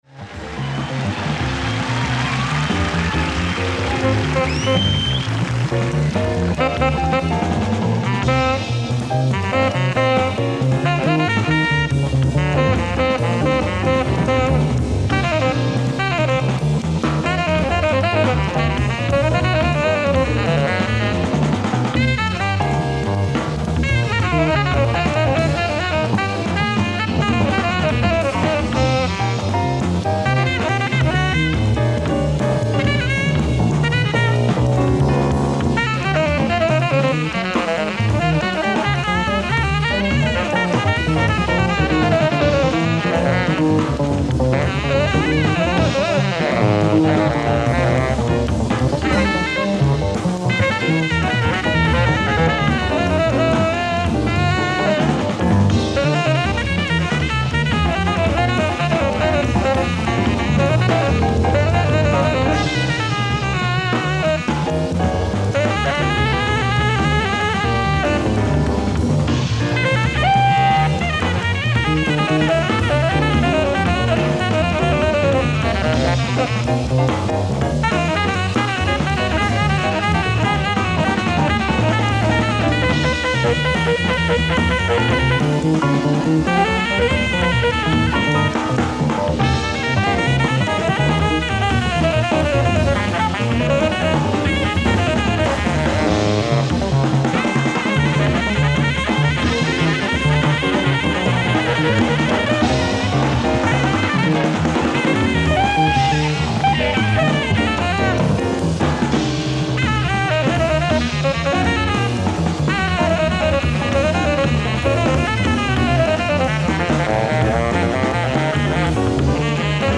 ライブ・アット・オープンシアター・イースト、東京 07/23/1981
※試聴用に実際より音質を落としています。